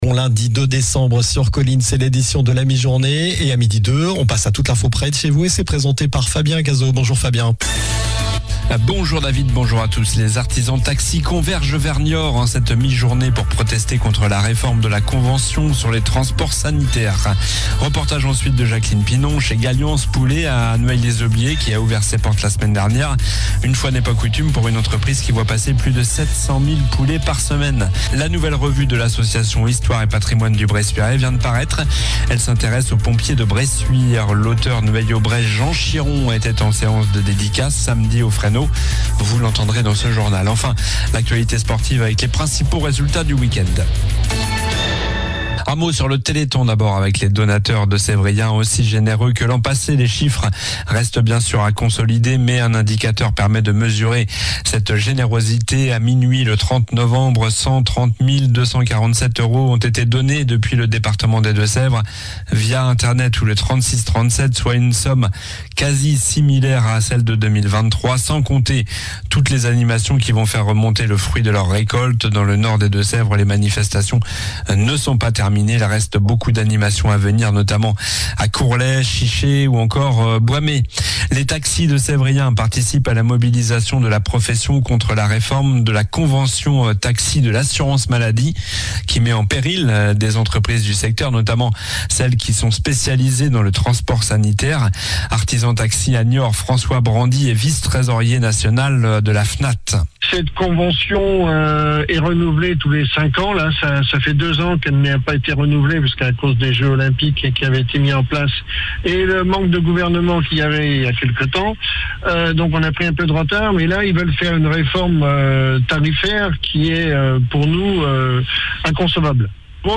Journal du lundi 02 décembre (midi)